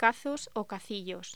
Locución: Cazos o cacillos
voz
Sonidos: Hostelería